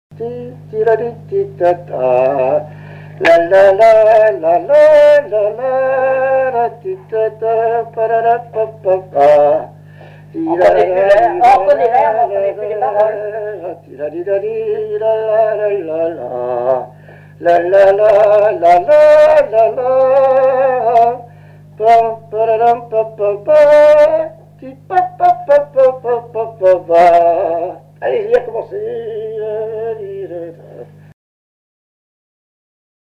Valse
danse : valse
Pièce musicale inédite